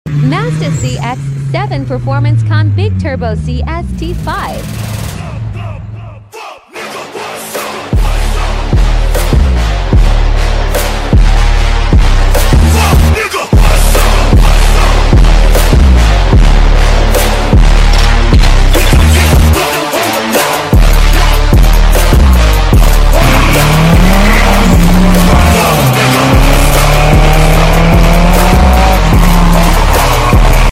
MAZDA CX7 PERFORMANCE CON BIG TURBO CST5